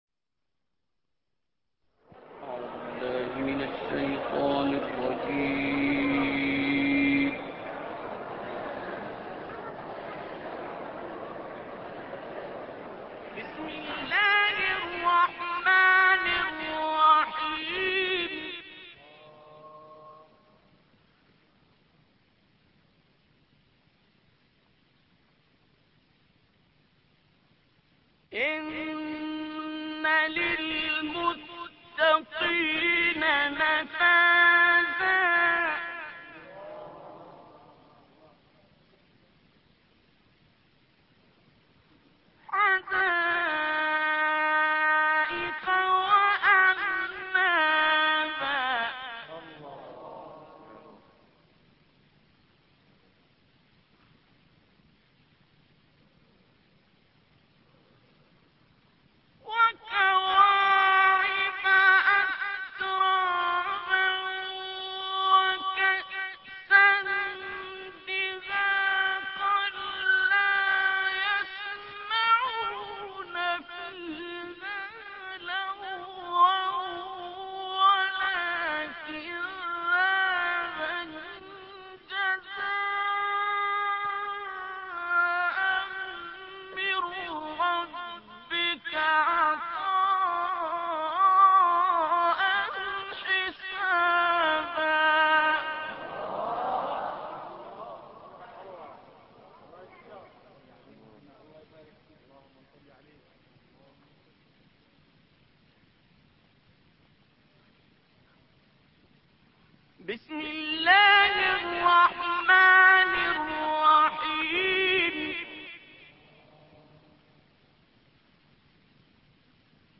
سورة النبأ ـ عبدالباسط ـ عجم ورست - لحفظ الملف في مجلد خاص اضغط بالزر الأيمن هنا ثم اختر (حفظ الهدف باسم - Save Target As) واختر المكان المناسب